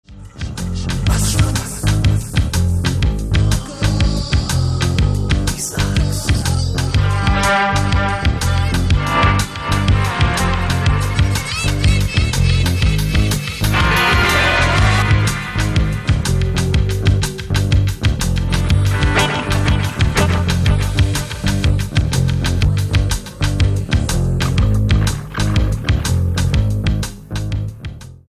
Disc 1 floors it with brutal punk shorts.